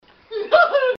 Laugh 34